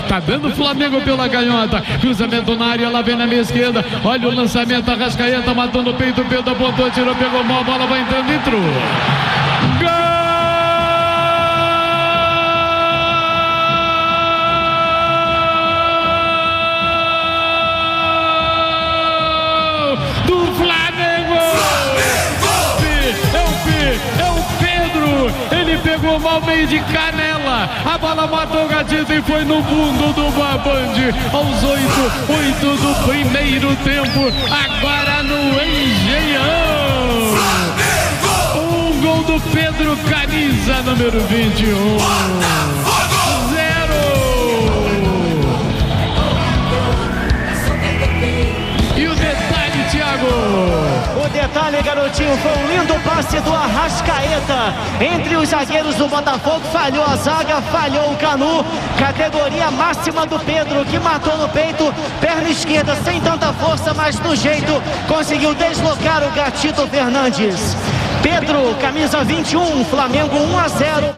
Ouça os gols da vitória do Flamengo sobre o Botafogo pelo Carioca com a narração do Garotinho